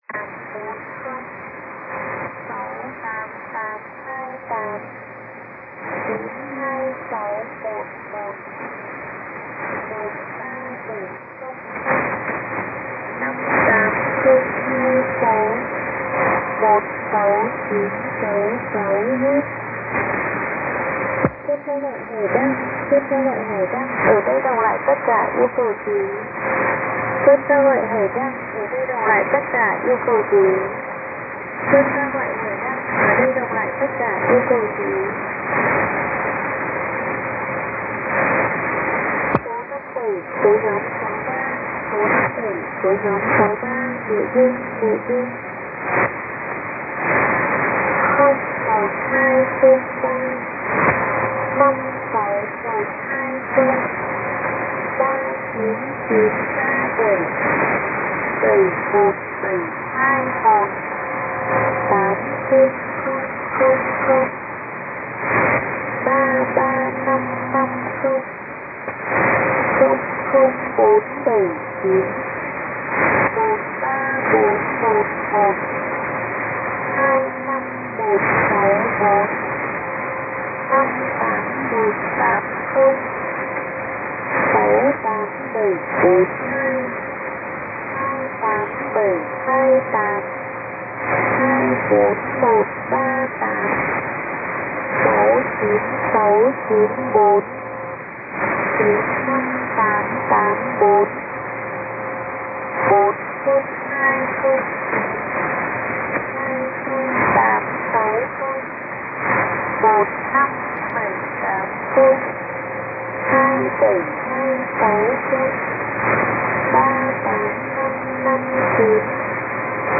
S6 or stronger here in Southern California.
The transmission consisted of a female voice, in Vietnamese, announcing groups of numbers.
I caught about 20 seconds of groups before there was a non-numeric announcement that was repeated several times.
After a short pause a new message started, with several lines of announcement and again into 5 figure groups.